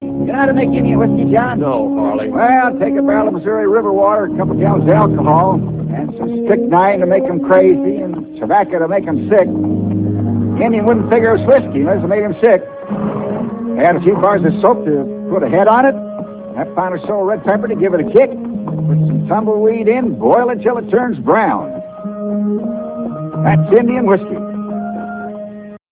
Whiskey.real audio-28kbHarley explaining how to make indian whiskey!